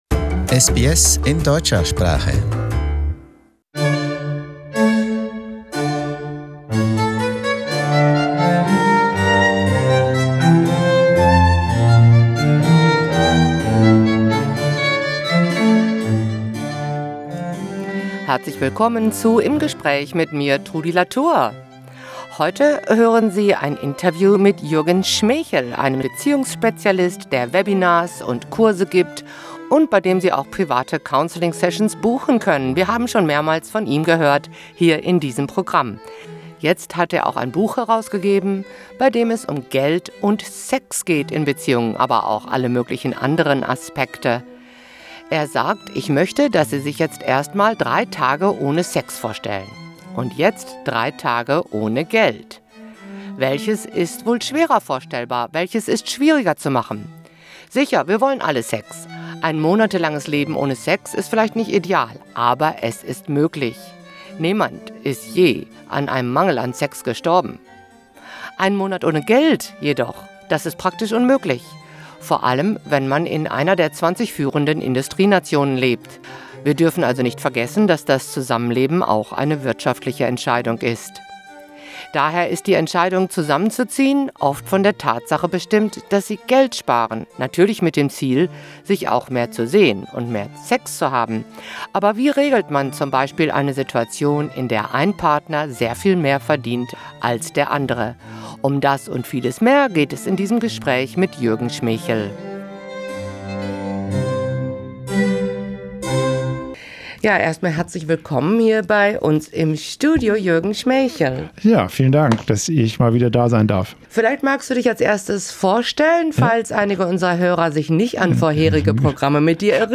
In conversation: What’s more important, Money or Sex?